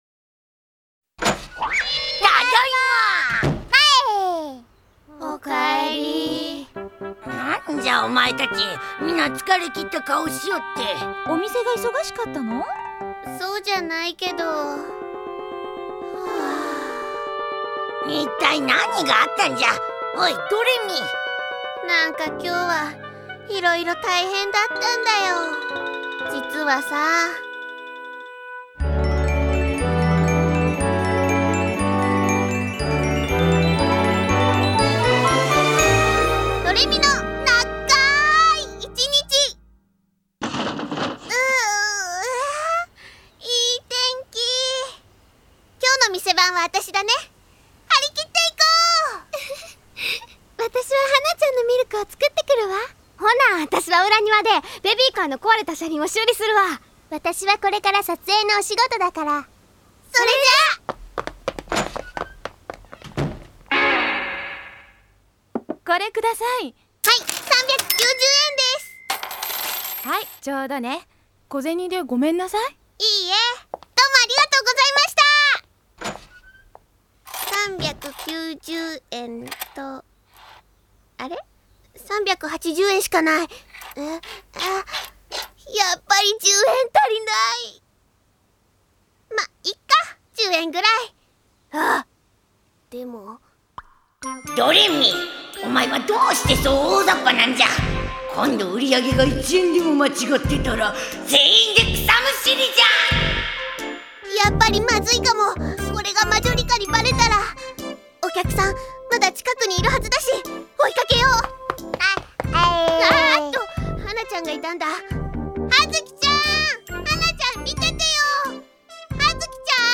09 - Mini Drama [Doremi no naggaaaai ichinichi].mp3